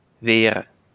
Holandia: IPA[ʋɪːr] ?/i
Belgia i poł. Holandia: [wɪːr] lub [β̞ɪːr]